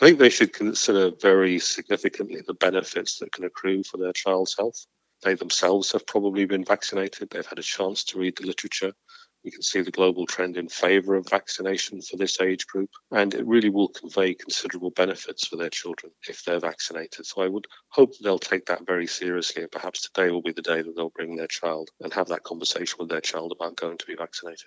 Former HSE director-general, Tony O’Brien, says parents shouldn’t be worried about getting their children vaccinated, due to the numerous proven health benefits of vaccination.